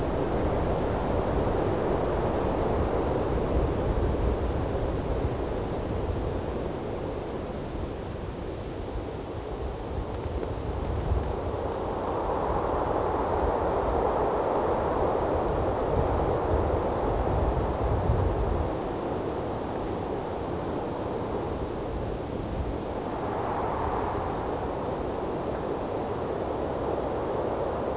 ambience.wav